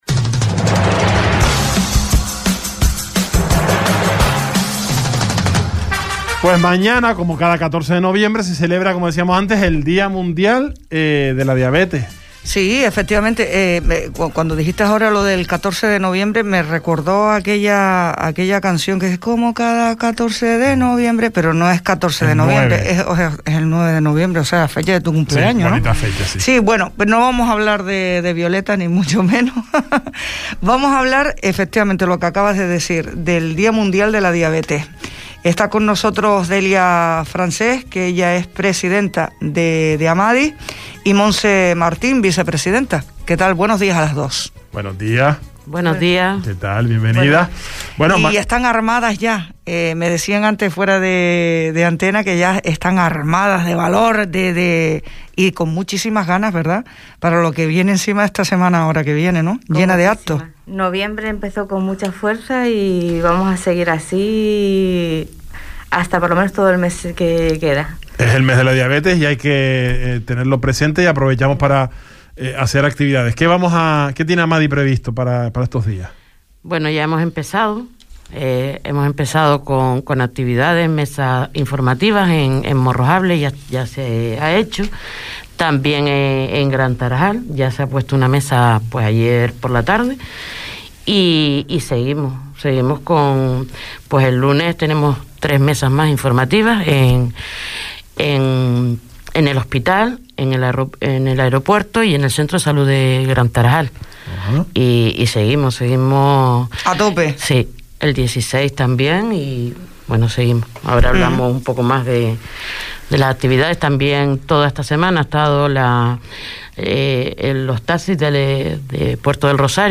El Salpicón | Entrevista